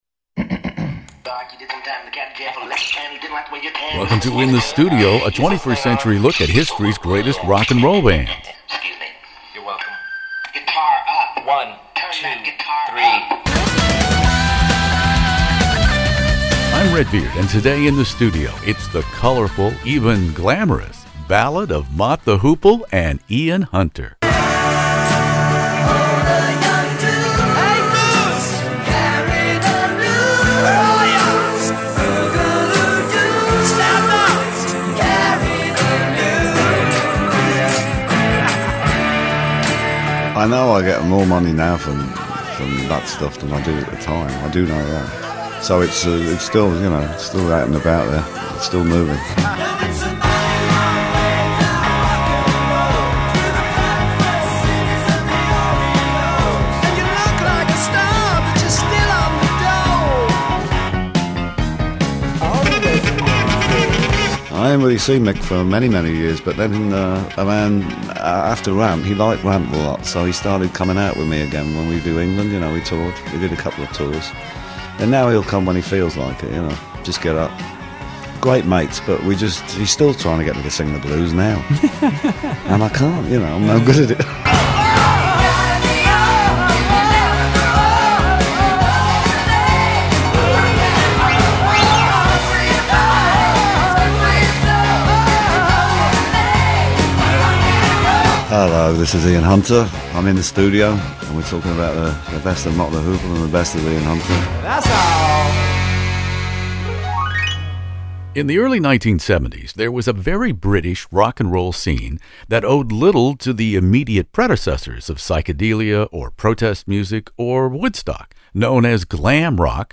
One of the world's largest classic rock interview archives, from ACDC to ZZ Top, by award-winning radio personality Redbeard.
We were so fortunate to have Mott the Hoople main man Ian Hunter join me In the Studio for a career retrospective, centered around All the Young Dudes and the July 1973 Mott.